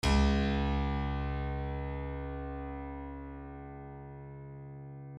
piano-sounds-dev
piano-sounds-dev / HardPiano / cs1.mp3